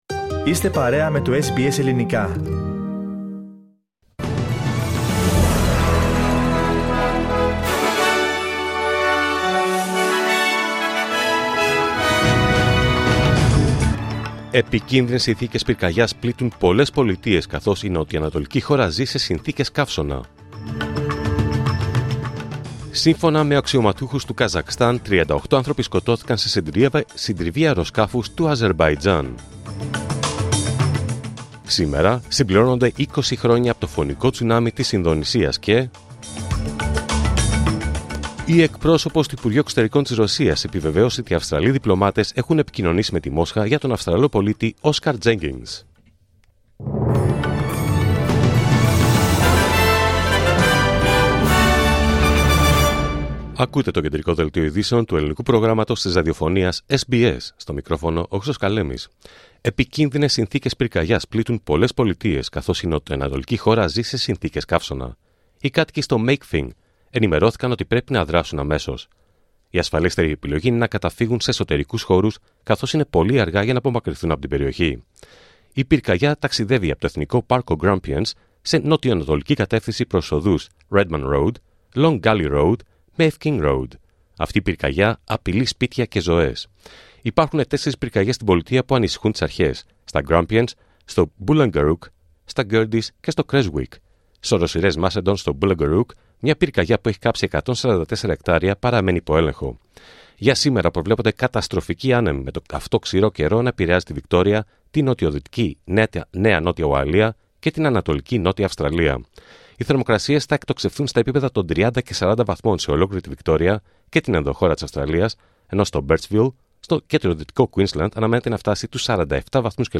Δελτίο Ειδήσεων Πέμπτη 26 Δεκέμβριου 2024